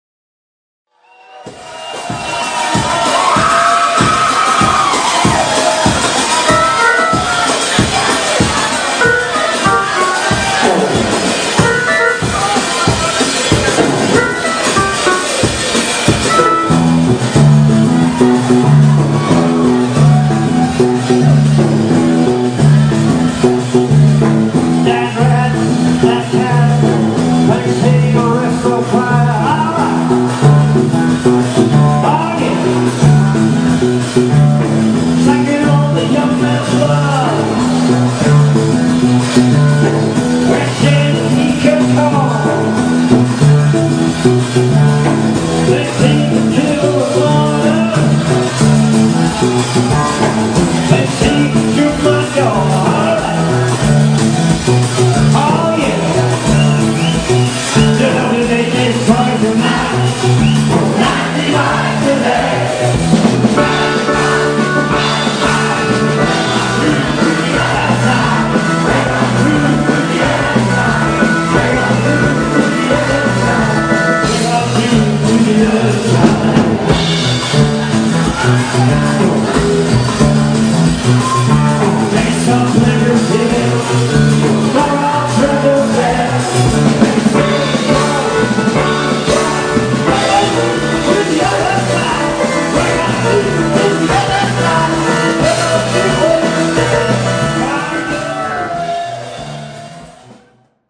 absolutely live mp3-Soundfiles